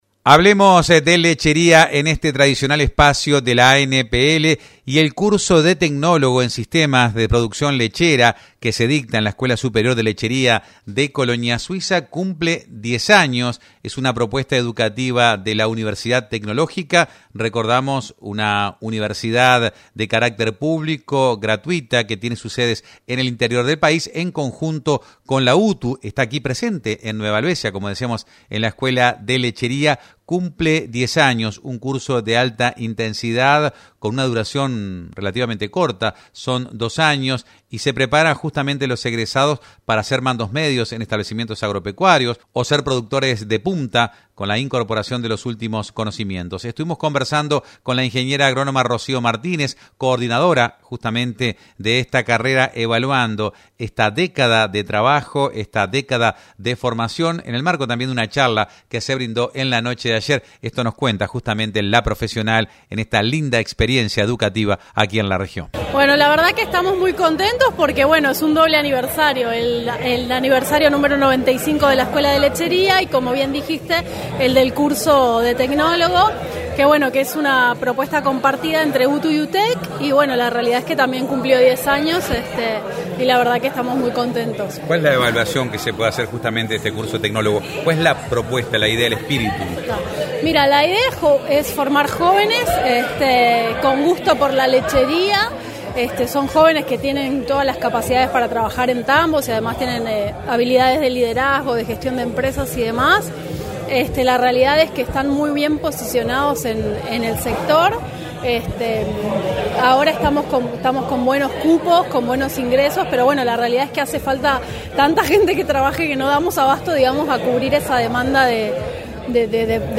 Dialogamos en Mediodia Rural